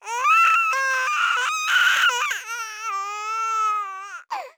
fixed baby sounds
sob.wav